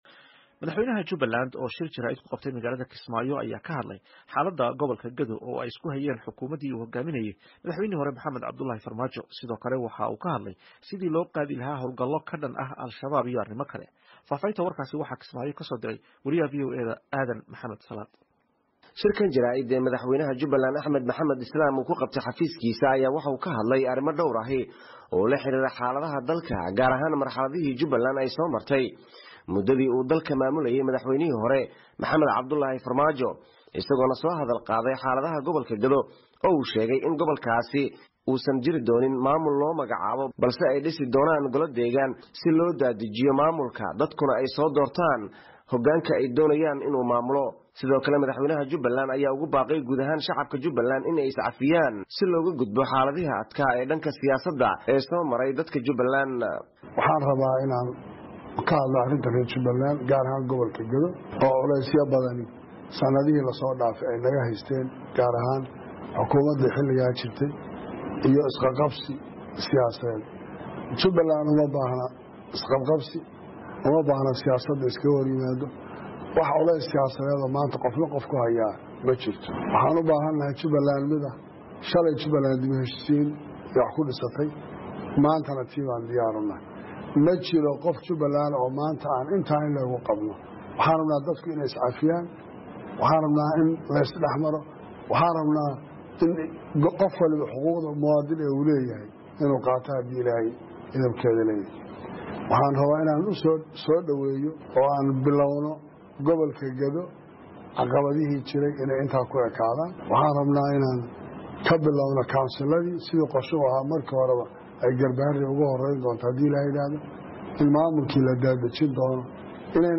Kismaayo —